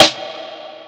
snare (Dreams - 31dezember).wav